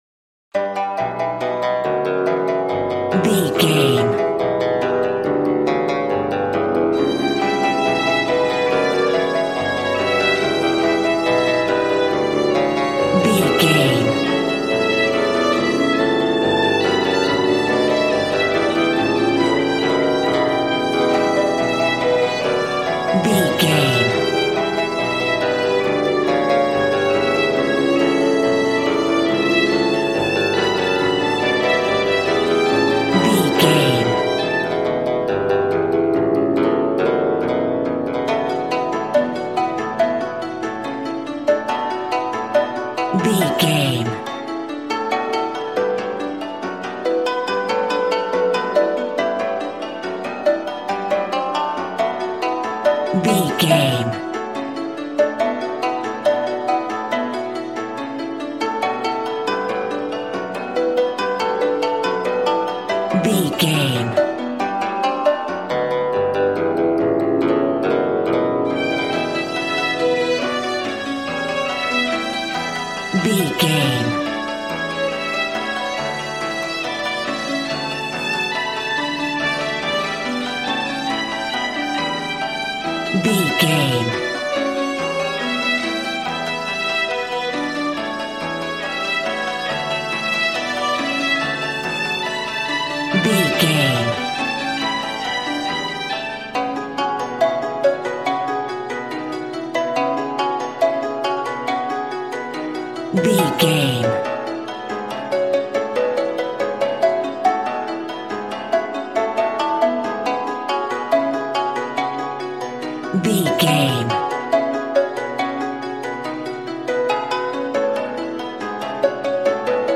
Ionian/Major
smooth
conga
drums